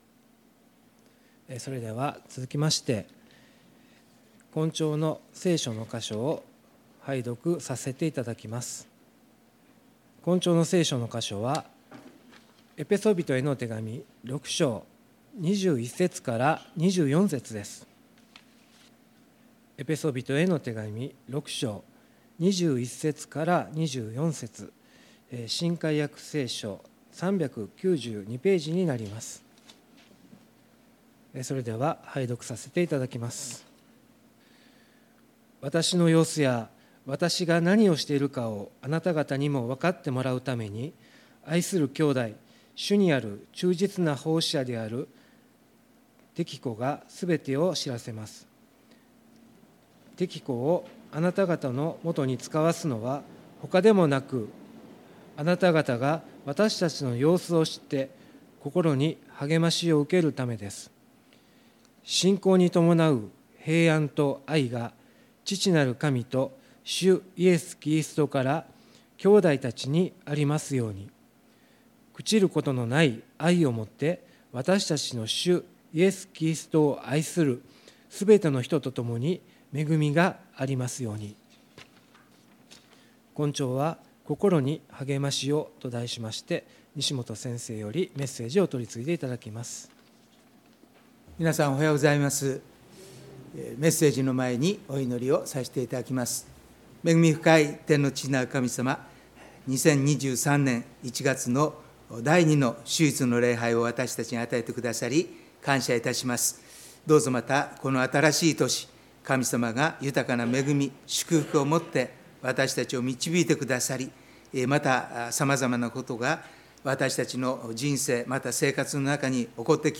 2023年 1/8 第二主日 新年聖餐礼拝